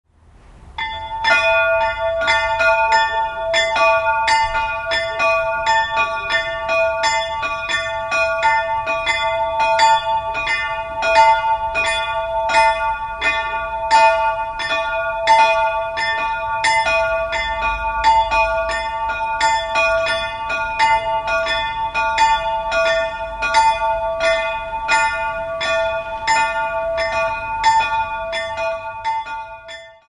Die Glocken der Friedhofskirche St. Sebald
Der schlanke Turm der Friedhofskirche St. Sebald trägt zwei kleine Gussstahlglocken. Sie erklingen in den Tönen e³ und a³ und wurden im Jahr 1948 vom Bochumer Verein für Gussstahlfabrikation in Bochum gegossen.